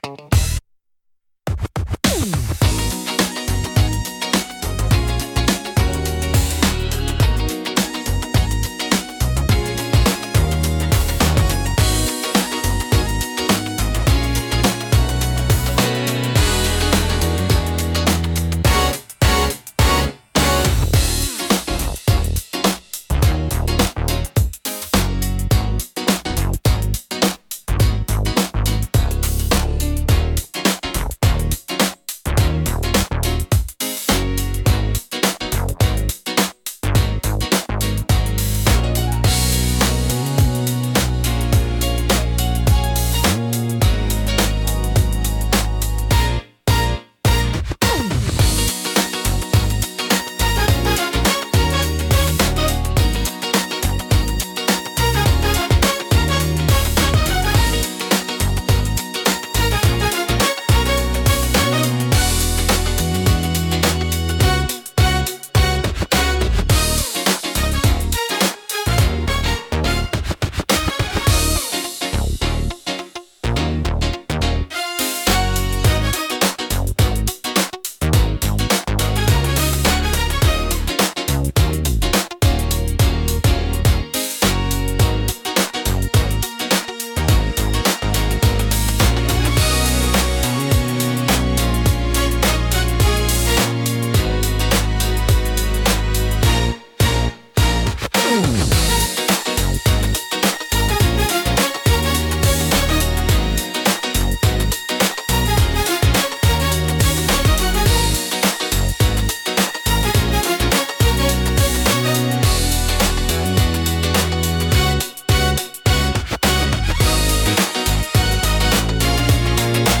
聴く人に心地よいリズム感と温かみを届ける、優雅で感性的なジャンルです。